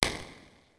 Geluidimpuls in een kamer.
Impulse_room2.wav